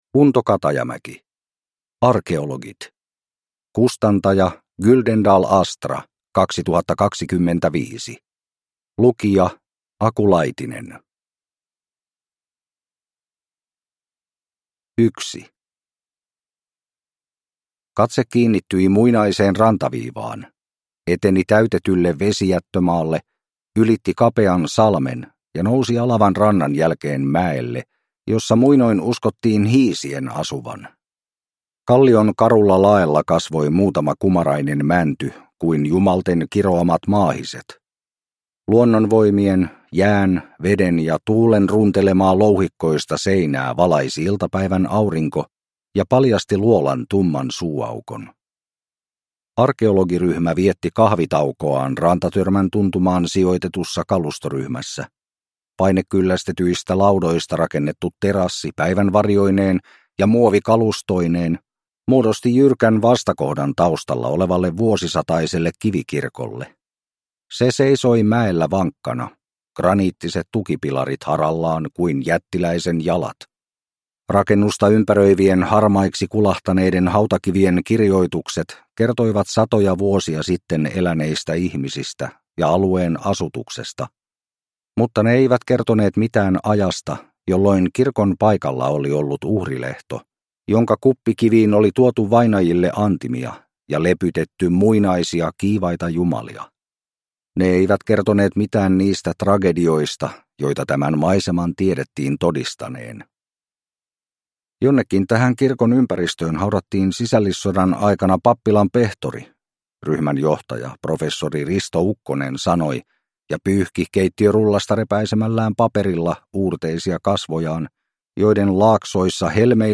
Arkeologit (ljudbok) av Unto Katajamäki